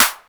Snare_13.wav